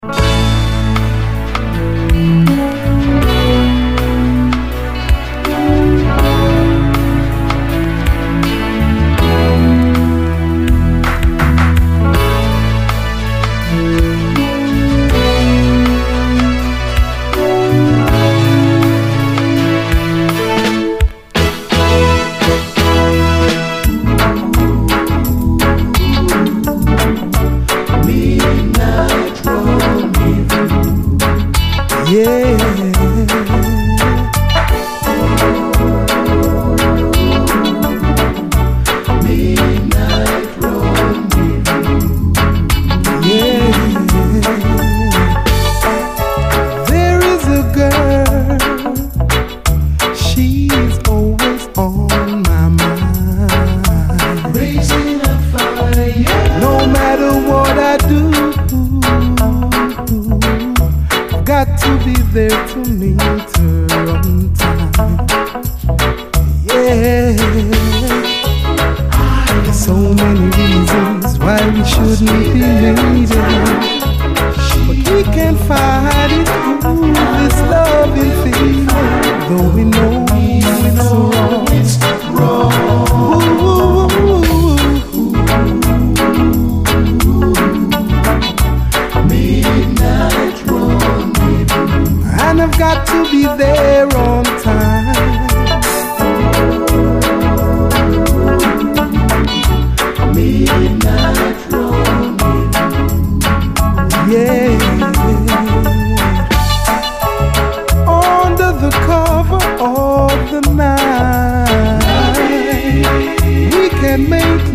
濃密にスモーキーな香りが立ちこめるメロウ・ラテン・ファンク
ブレイキンな轟音ラテン・ロック
ファットでルーディーなブーガルー